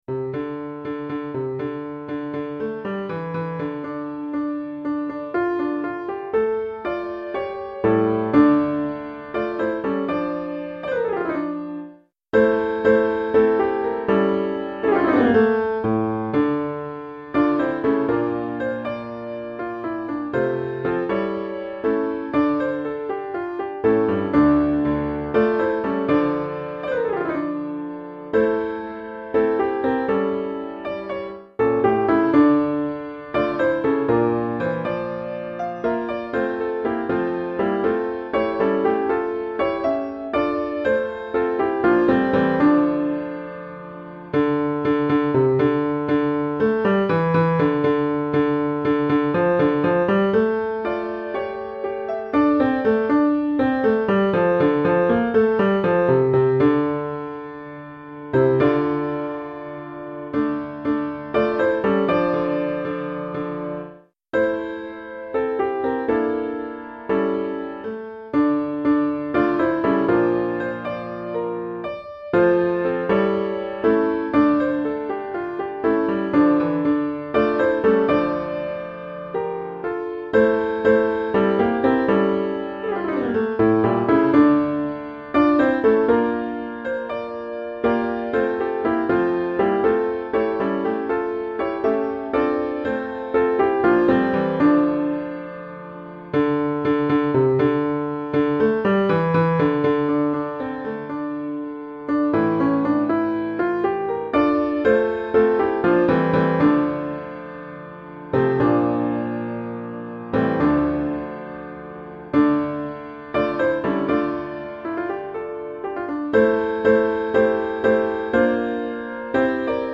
Six unaccompanied voices welcome the Sabbath